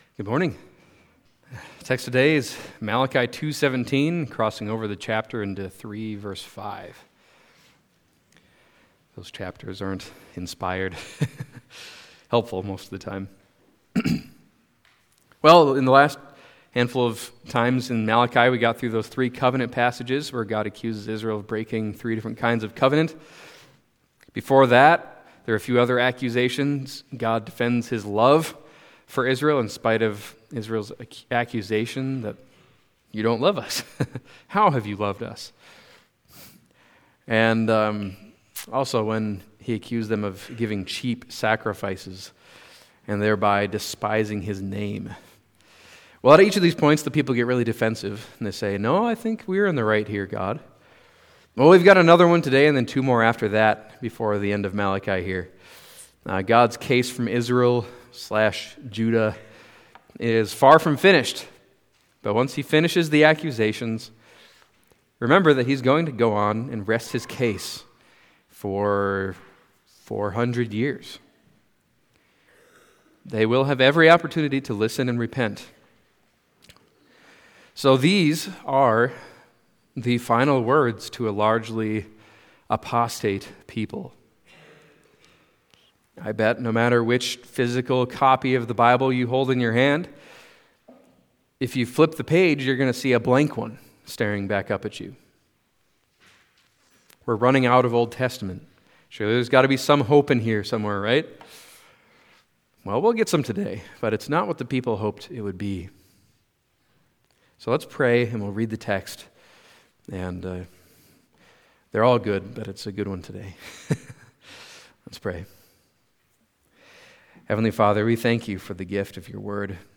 Malachi Passage: Malachi 2:17-3:5 Service Type: Sunday Morning Malachi 2:17-3:5 « Profaning the Covenant